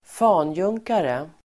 Ladda ner uttalet
Folkets service: fanjunkare fanjunkare substantiv, master sergeant , warrant officer Uttal: [²f'an:jung:kare] Böjningar: fanjunkaren, fanjunkare, fanjunkarna Definition: militärgrad närmast över sergeant